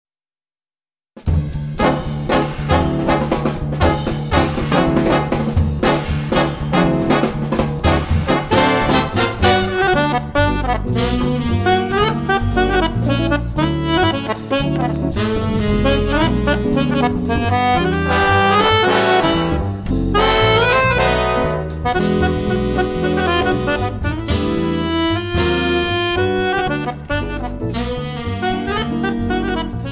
big band
swing music